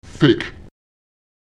Lautsprecher vek [fEk] vor (Handlung – Gegenwart)